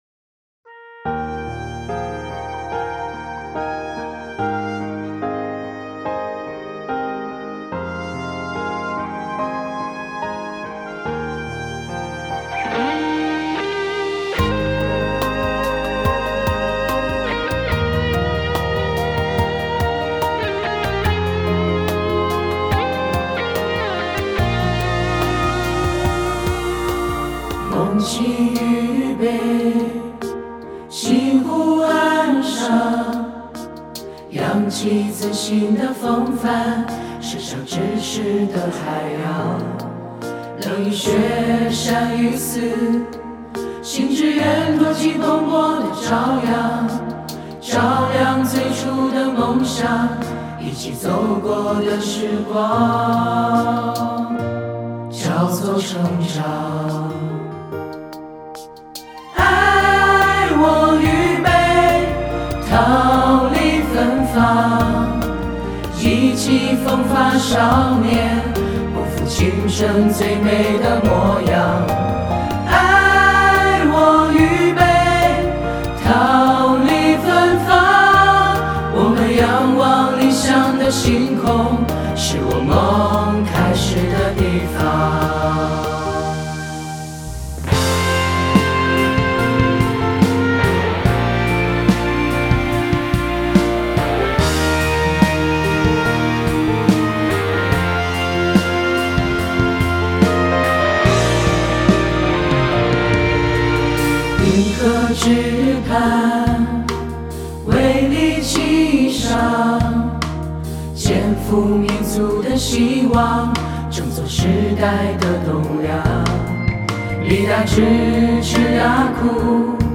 校歌MP3